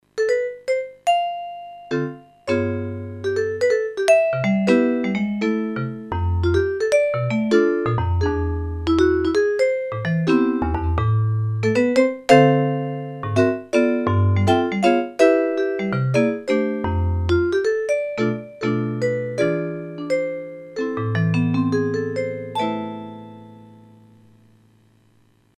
16 bit True Stereo Piano Module (year 1994)
Hear vibes
demo vibes